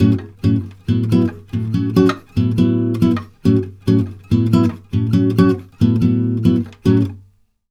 140GTR D7  2.wav